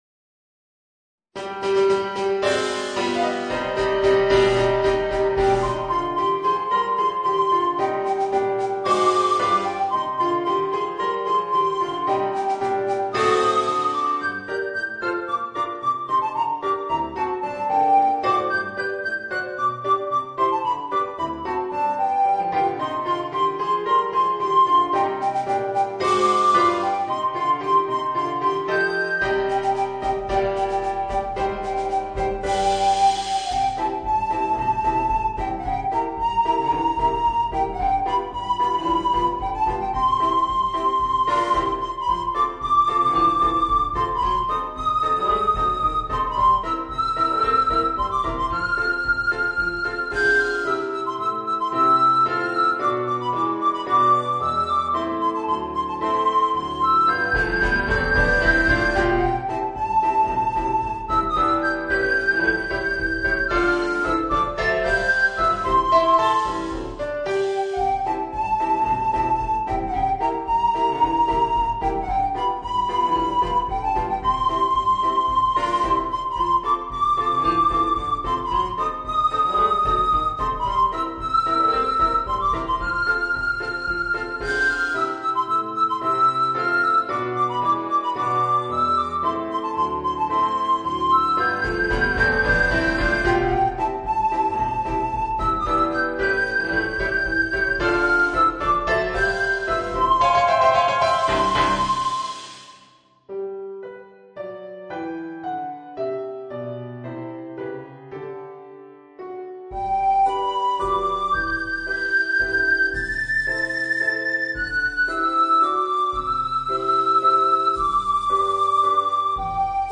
Voicing: Soprano Recorder and Piano